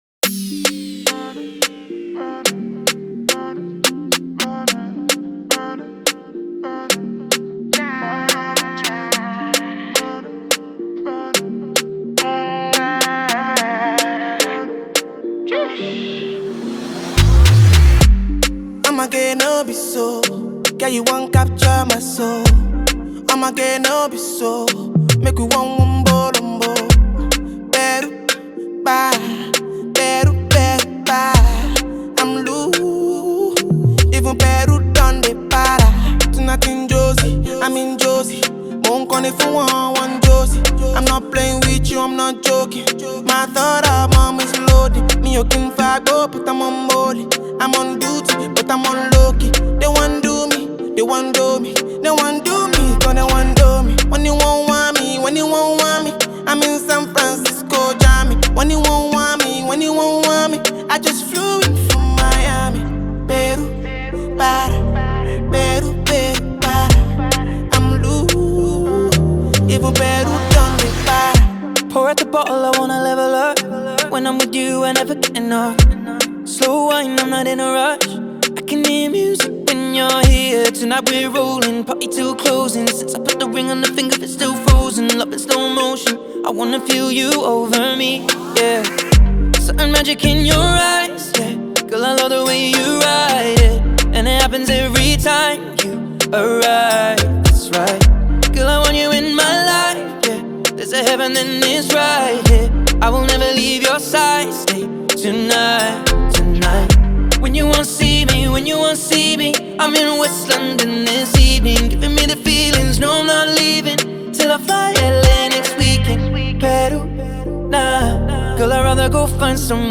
The song is officially owned by Nigerian Afrobeat songster
Their sound flows, cadence and delivery are so top-notch.